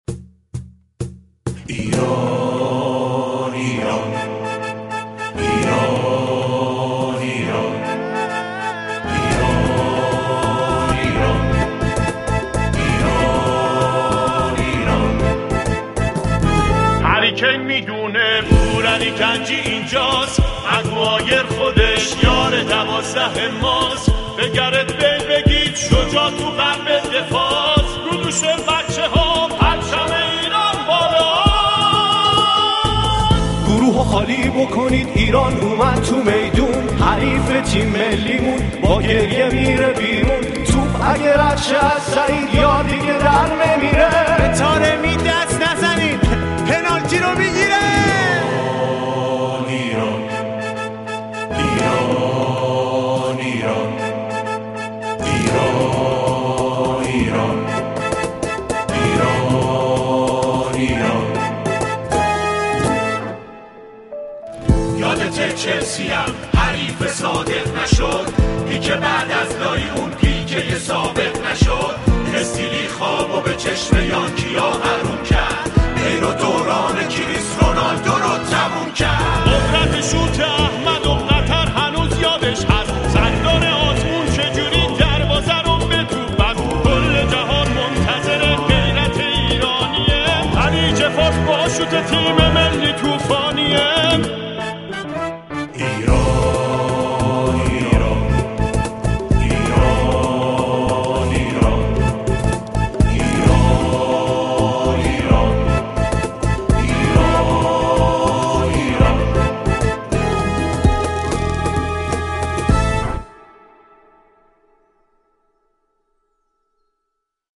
با درون مایه طنز